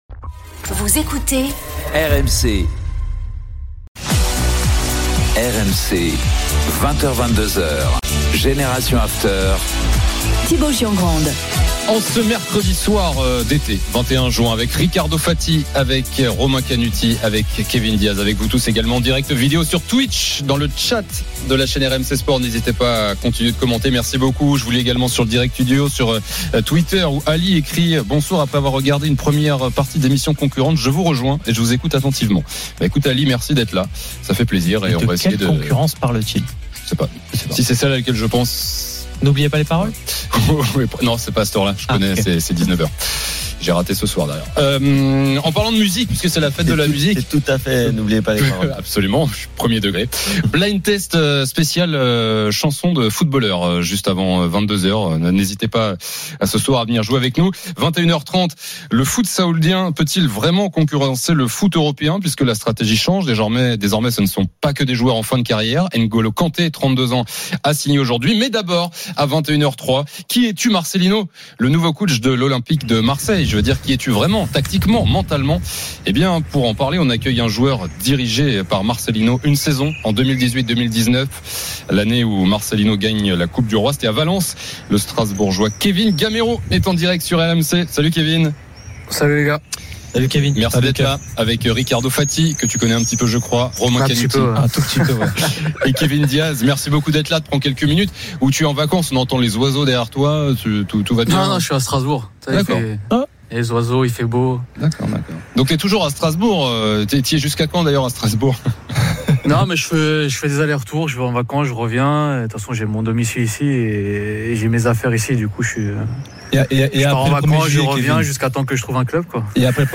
Le Top de Génération After : Kevin Gameiro invité de Generation After pour évoquer la méthode Marcelino, son ancien coach à Valence – 21/06
RMC est une radio généraliste, essentiellement axée sur l'actualité et sur l'interactivité avec les auditeurs, dans un format 100% parlé, inédit en France.